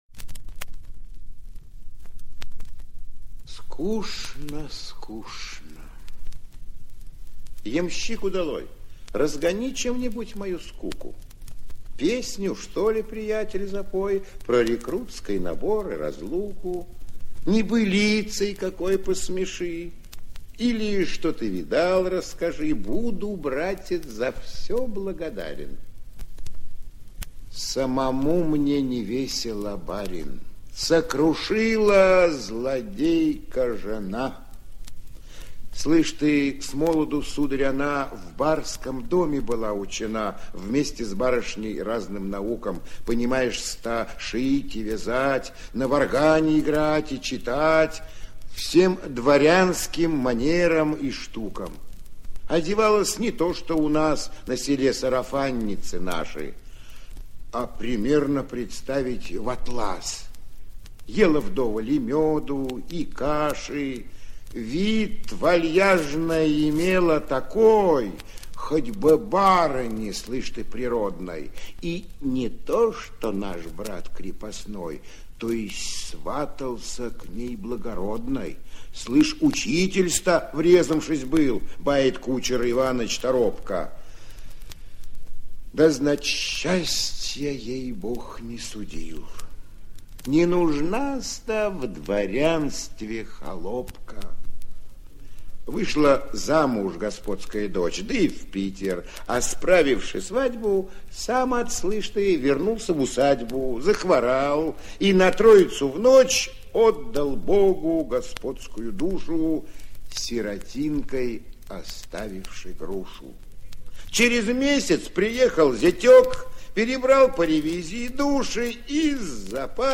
3. «(МБ) Исп. Алексей Грибов – Н.Некрасов – В дороге» /